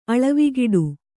♪ aḷavigiḍu